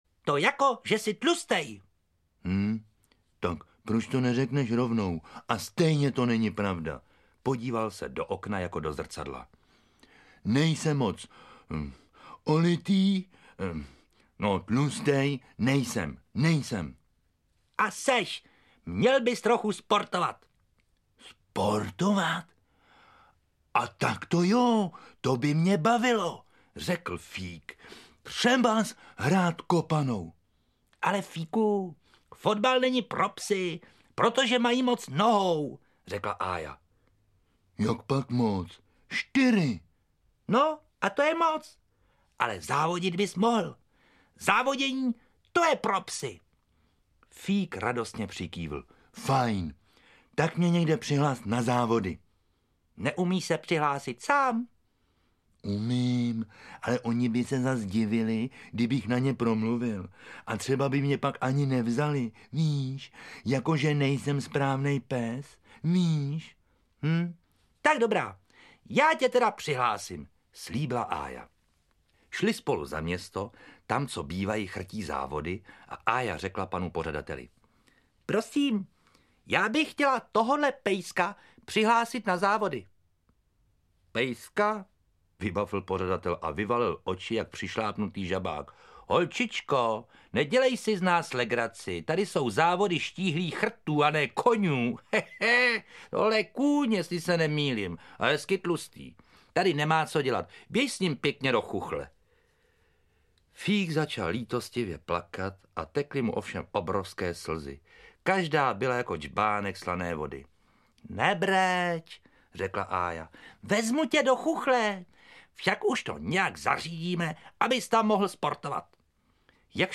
Audioknihy
Autorem pohádek je Jiří Žáček, čte Petr Kostka.